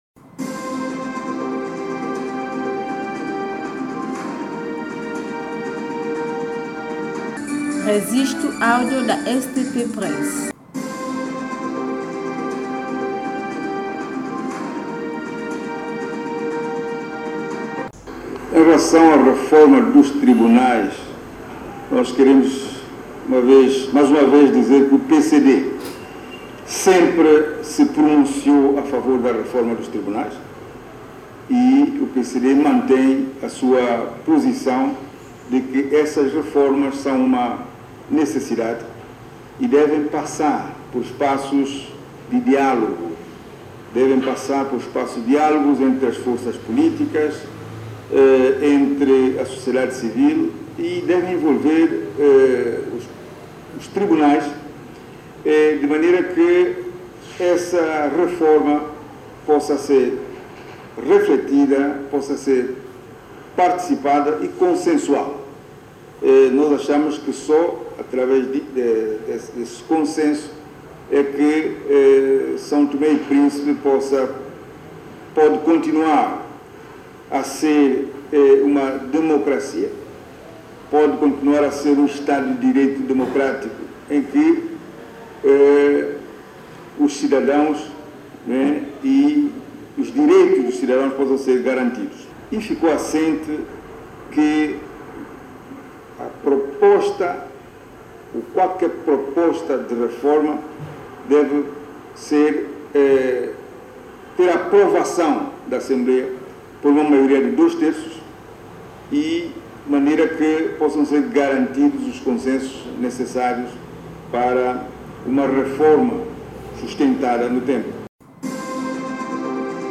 Em declarações a imprensa, o presidente do Partido da Convergência Democrática, Arlindo Carvalho sublinhou que “nós queremos mais uma dizer que PCD sempre se pronunciou a favor da reforma dos tribunais … mas devem passar por passos diálogo”.
Declaração do Presidente do PCD, Arlindo Carvalho